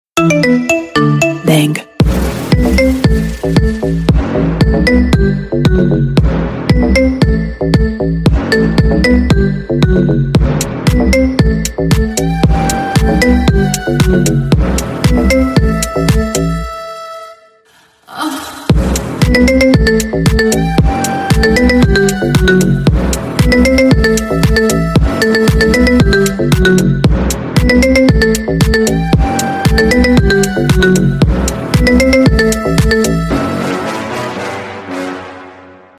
Рингтоны Без Слов
Рингтоны Ремиксы